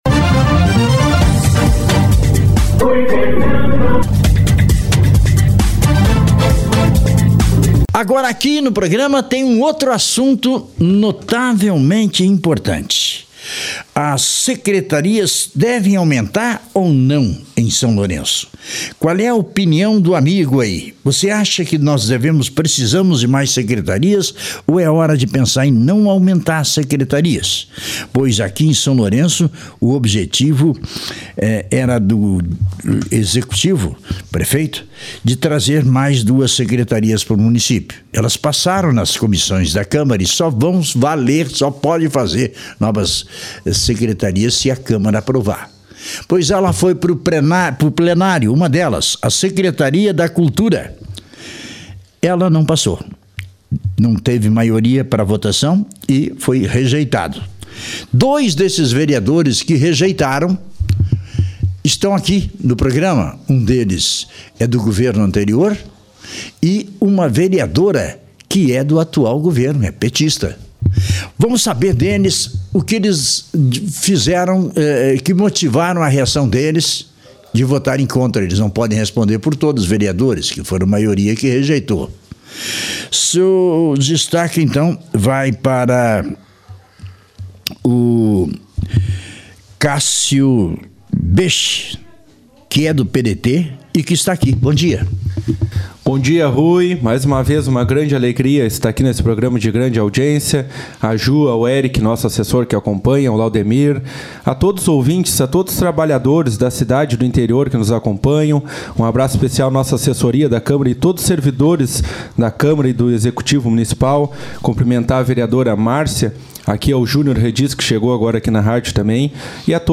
Os vereadores Cássio Boesche (PDT) e Márcia Lucas (PT) expressam em entrevista ao SLR RÁDIO desta quarta-feira (4) o porquê votaram contra a criação da Secretaria de Cultura, em Sessão Plenária na última segunda, 2 de junho.